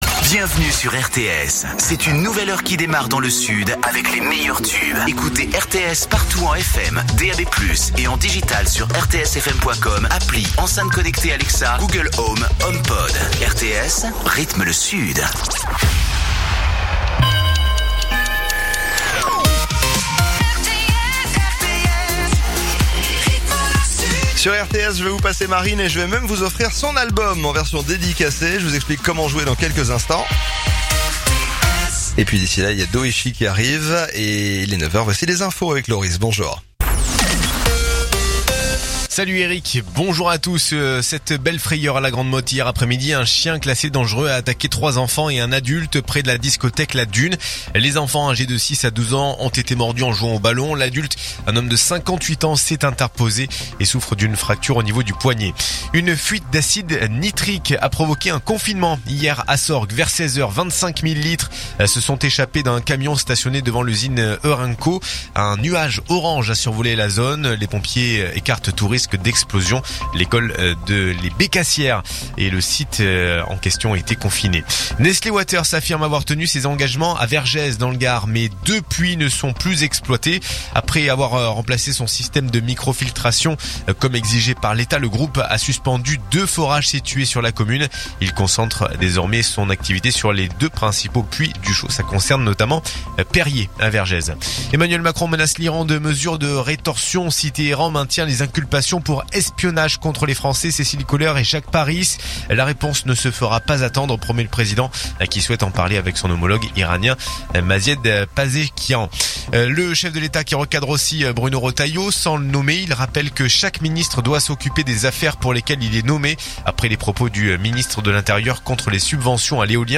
Écoutez les dernières actus de Narbonne en 3 min : faits divers, économie, politique, sport, météo. 7h,7h30,8h,8h30,9h,17h,18h,19h.